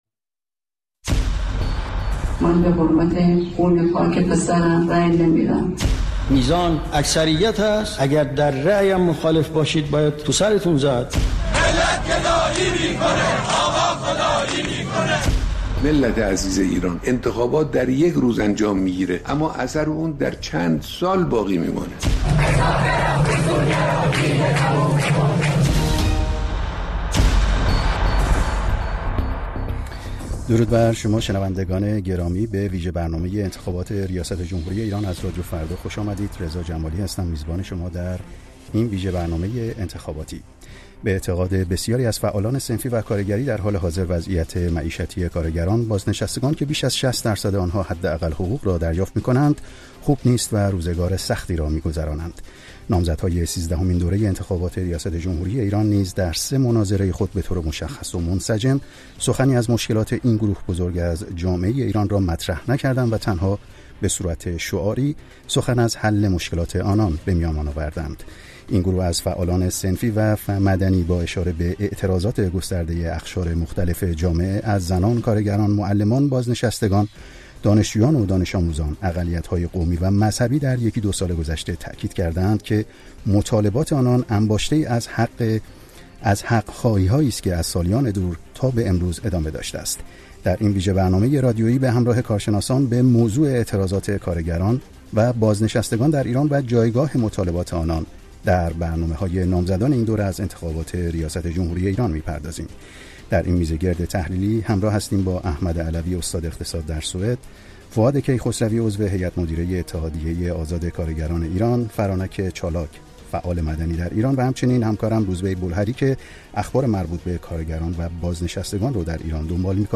میزگرد ویژه انتخابات: انتخابات و اعتراض؛ بازنشستگان و کارگران